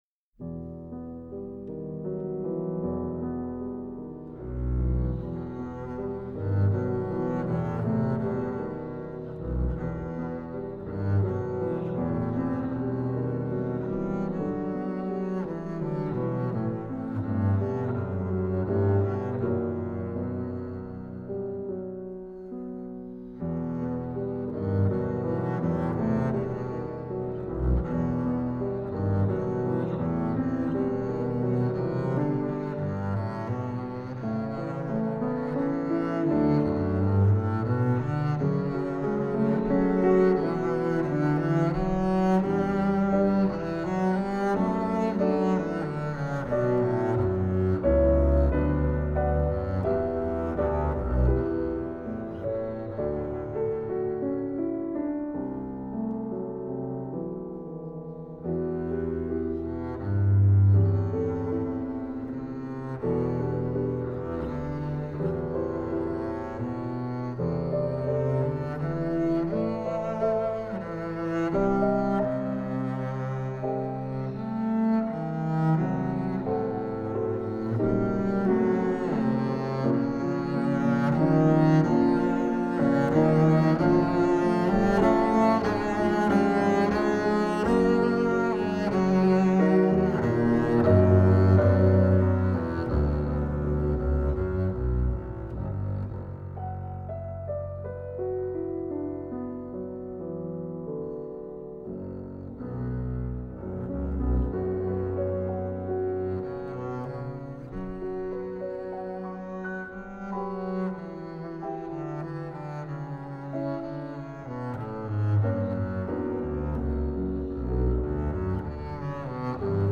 for double bass and piano